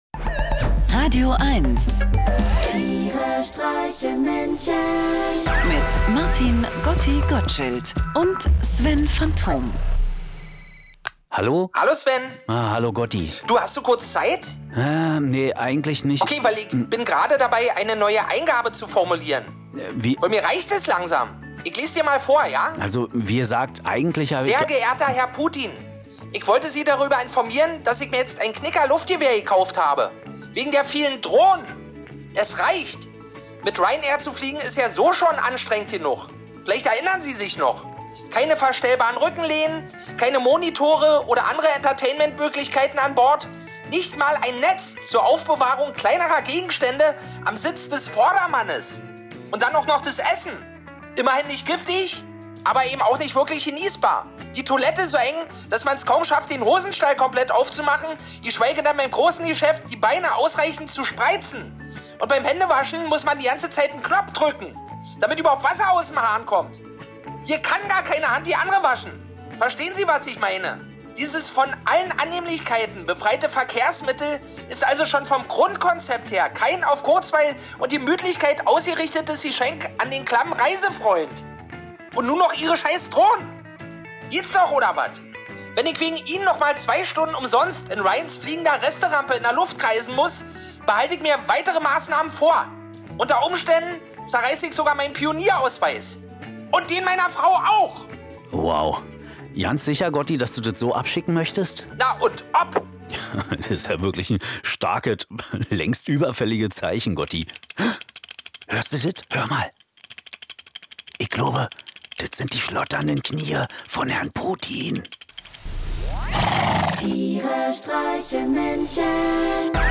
Comedy
Einer liest, einer singt